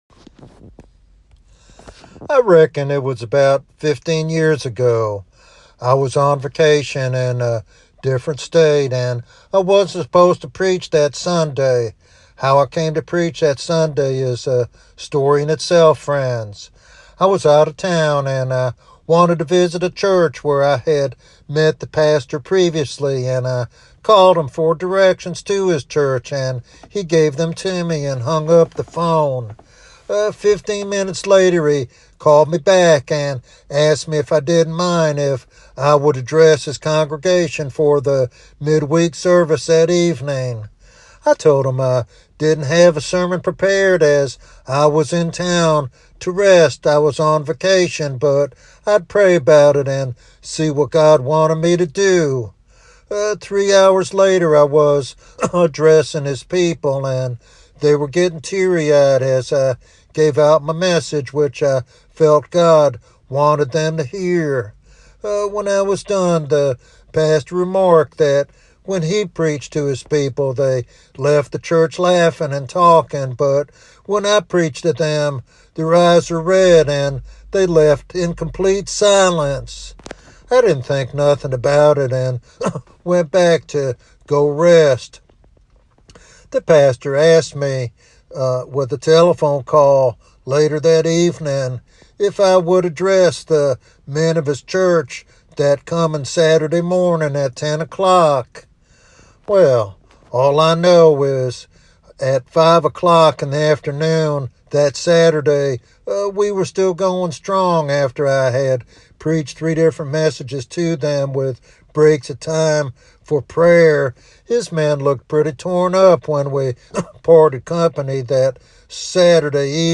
This sermon serves as both an encouragement and a call to spiritual responsibility within ministry.